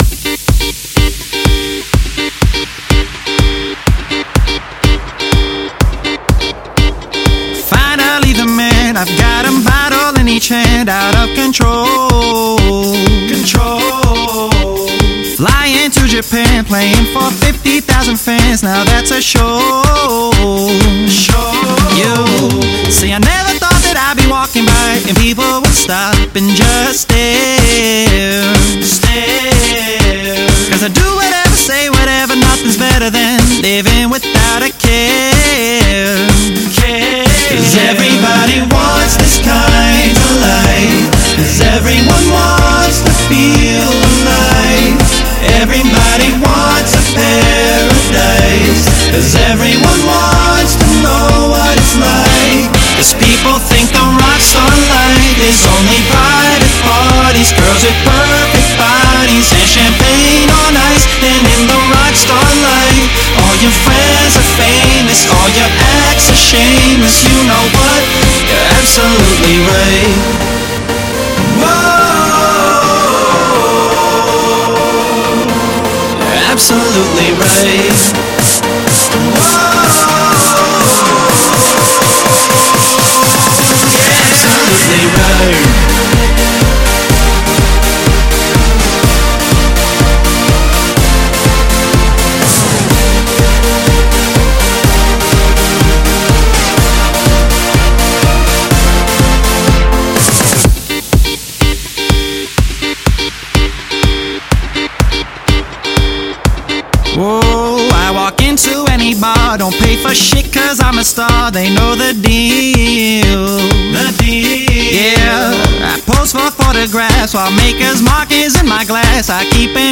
· by · in Pop.